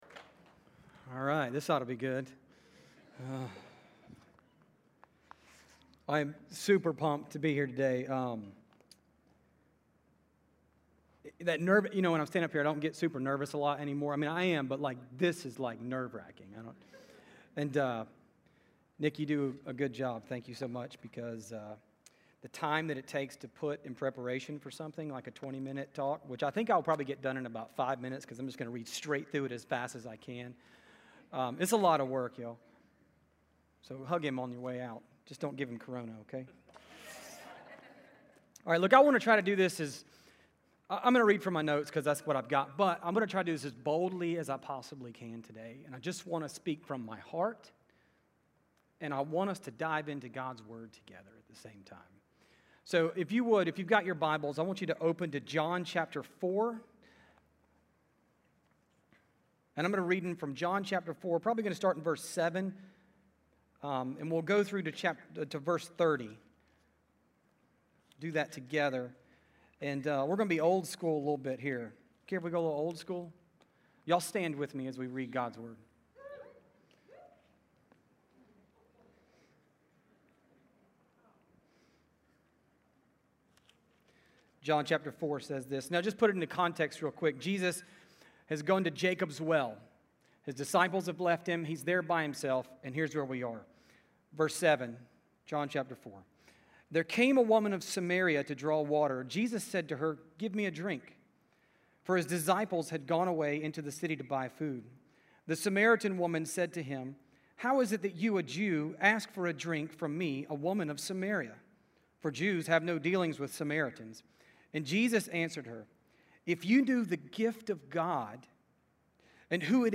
A message from the series "Faith Awakened."
Everyone has a story, listen to these followers of Jesus share their story on life before and after Jesus.